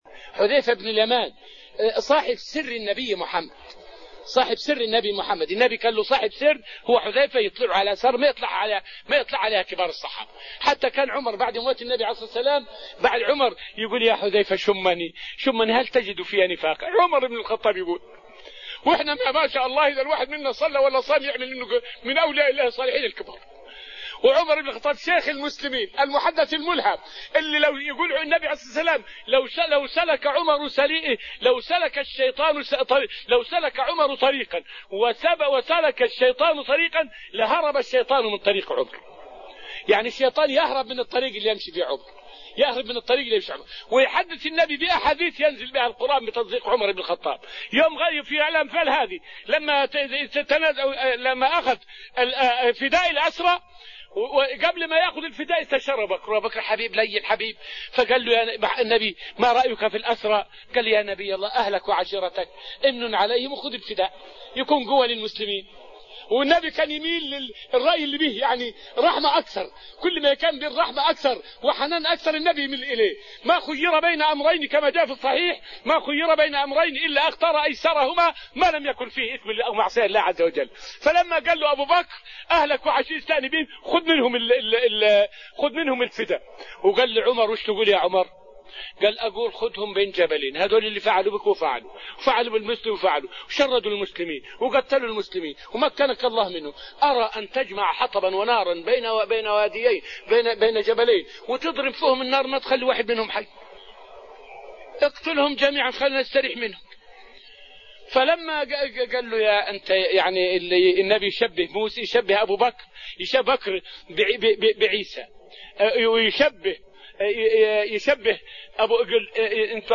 فائدة من الدرس الثامن من دروس تفسير سورة الأنفال والتي ألقيت في رحاب المسجد النبوي حول خوف سيدنا عمر من النفاق رغم عظمة إيمانه.